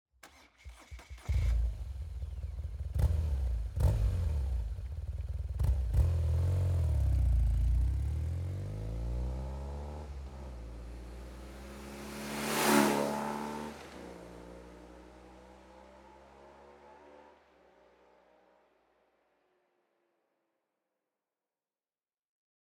BMW GS SOUNDCHECK
Dr. Jekill Mode - A calm, low-key but distinctive sound.
GS_Closed.mp3